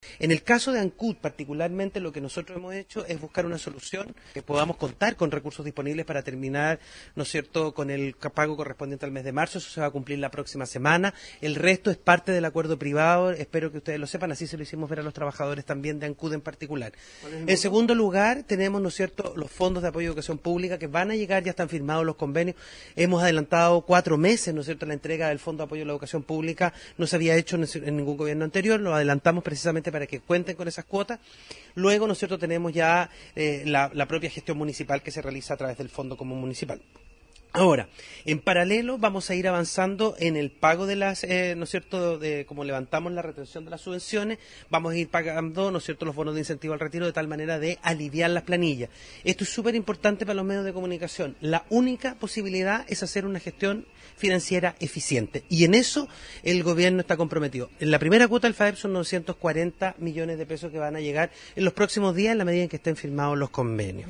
Escuchemos lo señalado por el ministro de Educación Marco Antonio Ávila sobre la situación de Ancud y las soluciones que se plantean.